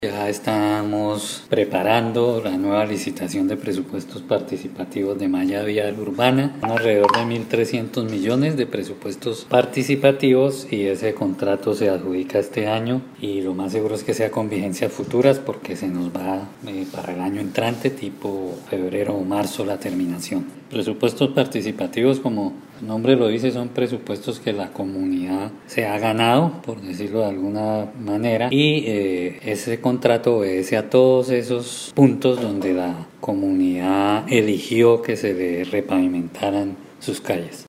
Descargue audio: Iván Vargas, secretario de Infraestructura
Ivan-Vargas-secretario-de-Infraestructura-Presupuestos.mp3